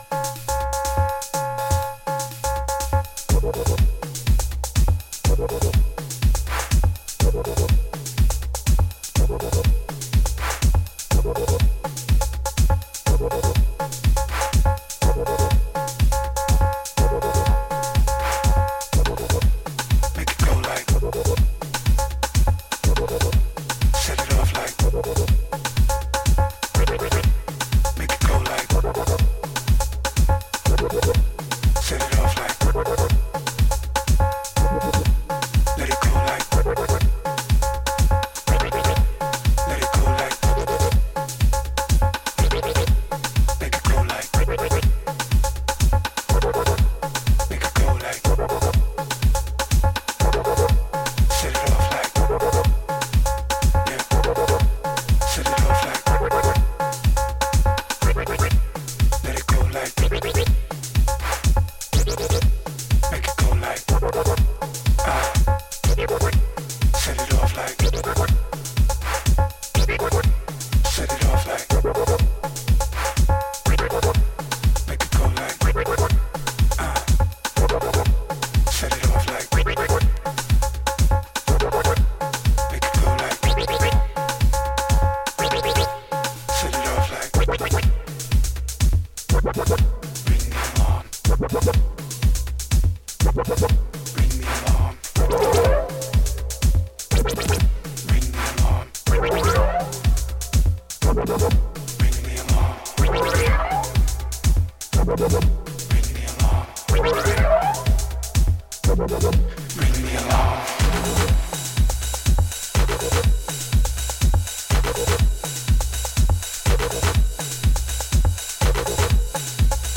vocalist
more stripped-back, bass-heavy early morning sleaze